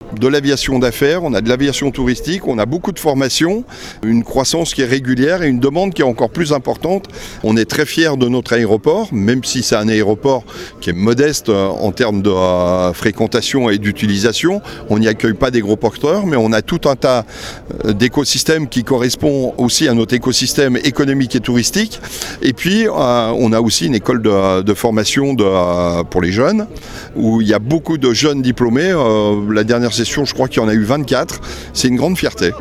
François Excoffier, Conseiller départemental délégué à l’économie et aux grands projets :